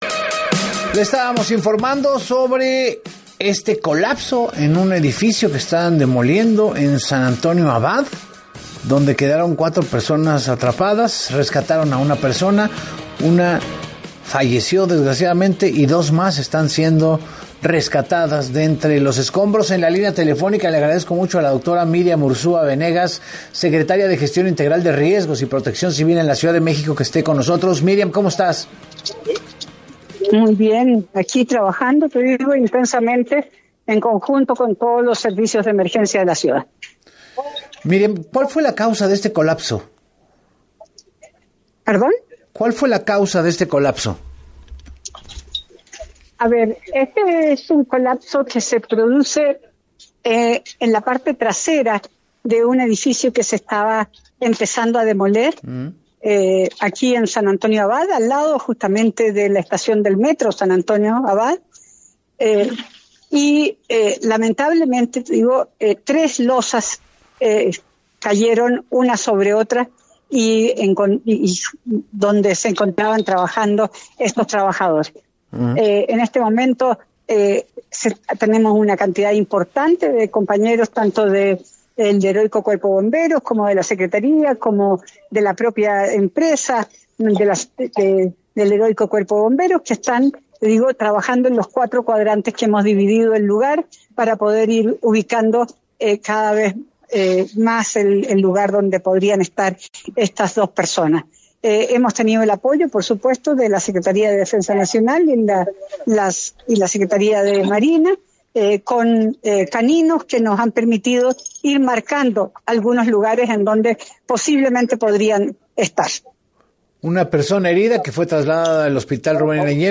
Reporte sobre el edificio colapsado en Calzada Tlalpan.
En entrevista para “Así Las Cosas con Enrique Hernández Alcázar”, la funcionaria aseguró que los trabajos son totalmente seguros y no han generado inestabilidad en los inmuebles aledaños, desmintiendo así las versiones de algunos vecinos que sugerían una conexión entre ambos eventos.